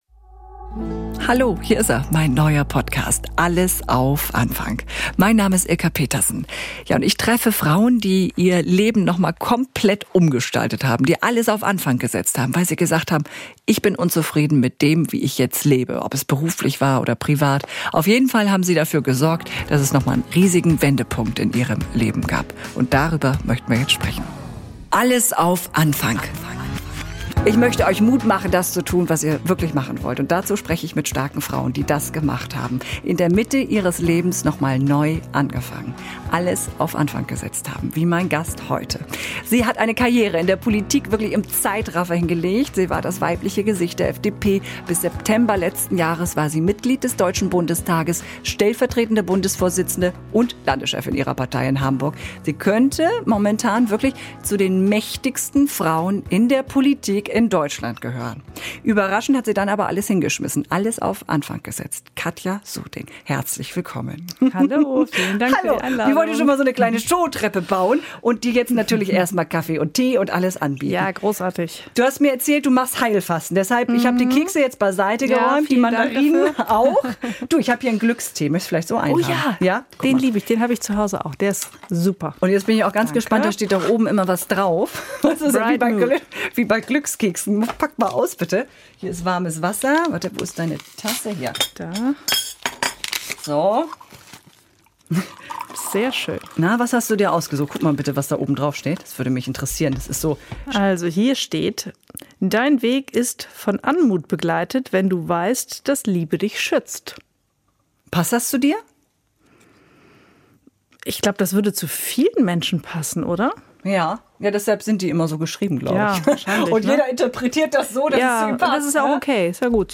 im Gespräch mit der ehemaligen FDP-Spitzenpolitikerin Katja Suding